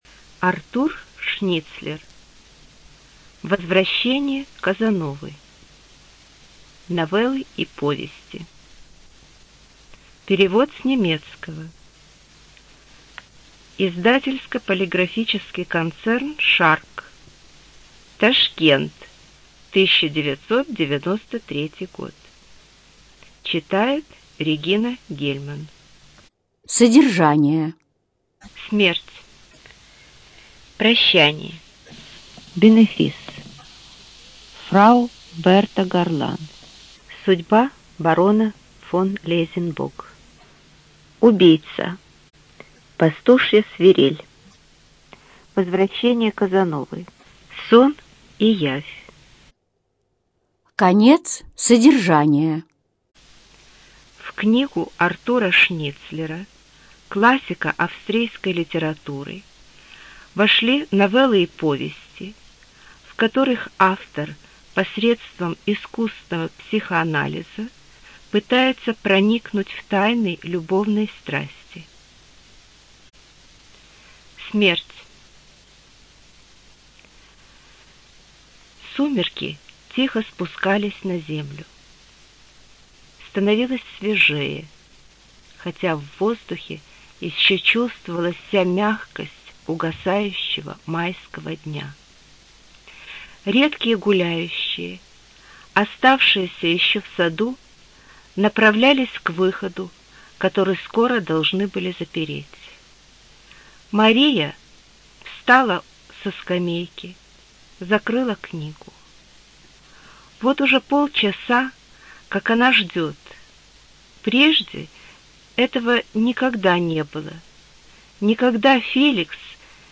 ЖанрКлассическая проза
Студия звукозаписиРеспубликанский дом звукозаписи и печати УТОС